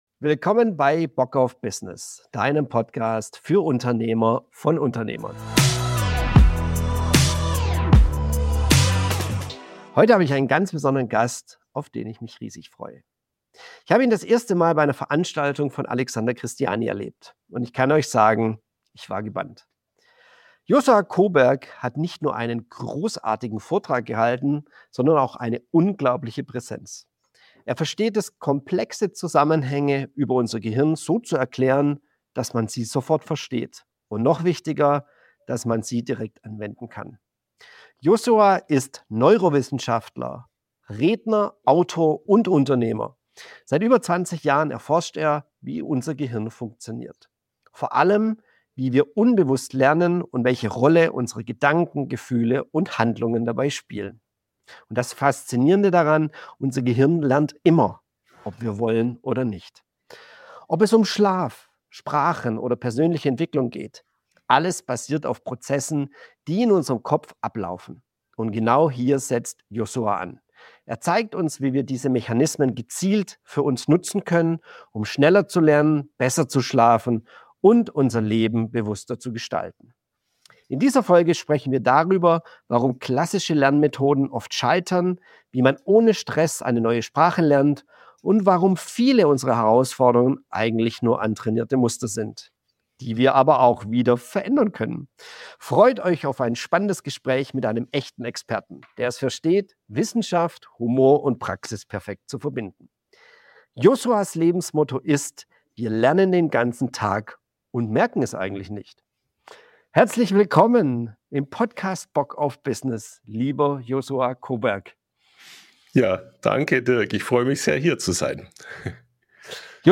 “Bock auf Business” – Der Podcast von Unternehmer für Unternehmer.